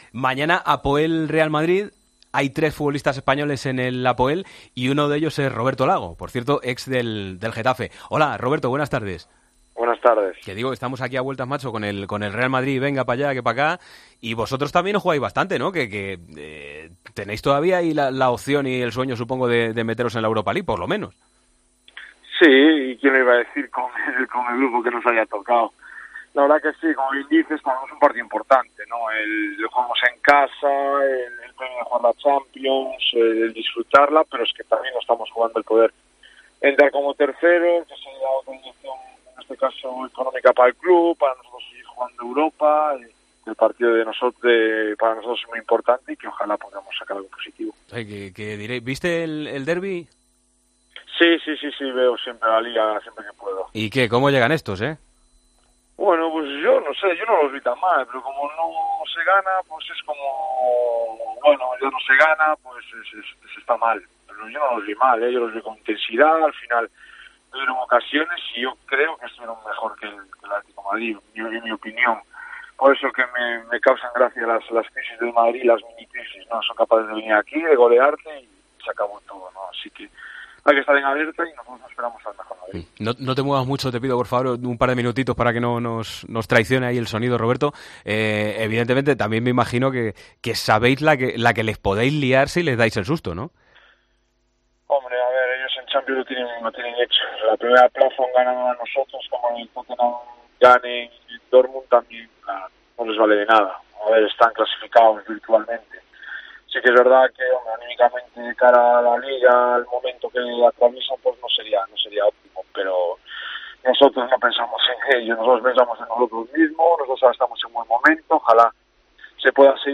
Hablamos con el jugador del Apoel el día previo del encuentro frente al Real Madrid: “Firmo el empate.